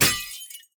glass3.ogg